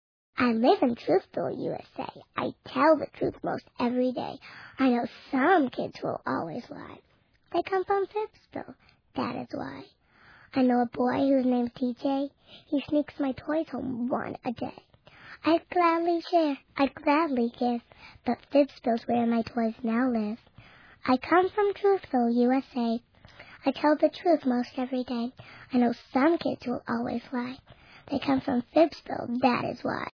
It's a collection of poems performed by kids, but For Kids By Kids is good for everybody big and little. Listeners will grin from ear to ear over the mispronounced words, the imaginative language and the expressiveness of each kid's unique voice.